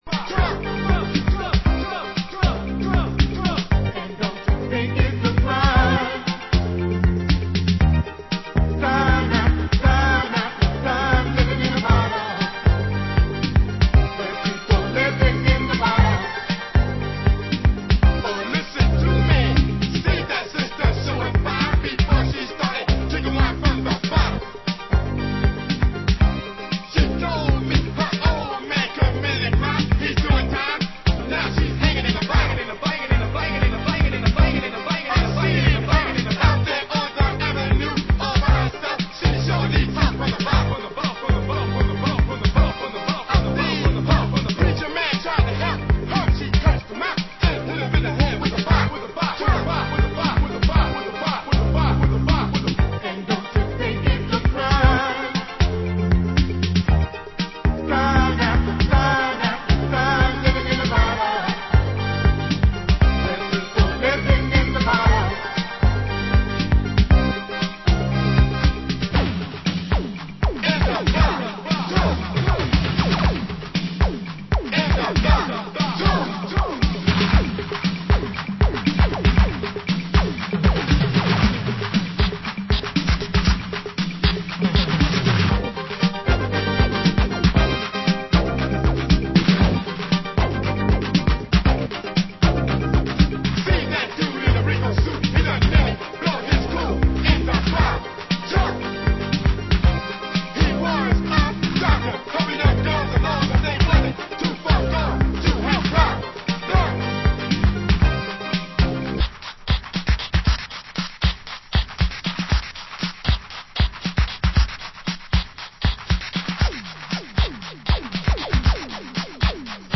Genre: Old Skool Electro
Instrumental